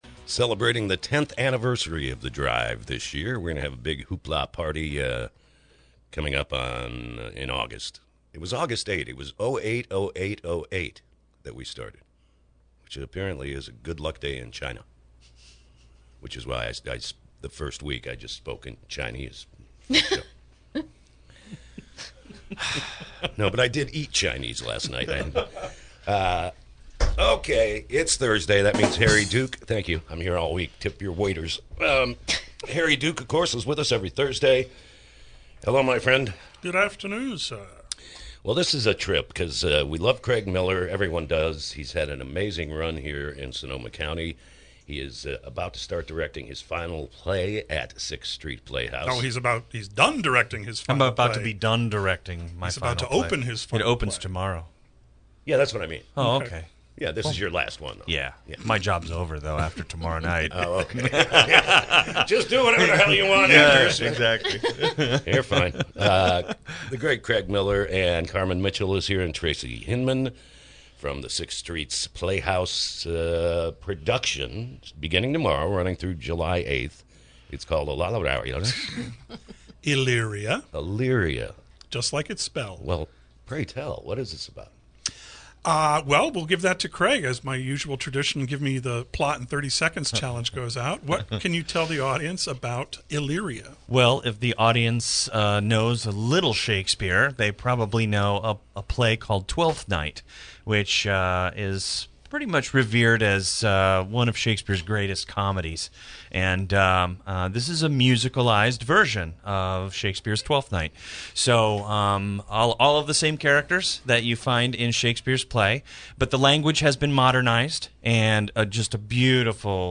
illyria-interviews.mp3